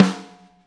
hit snare f.wav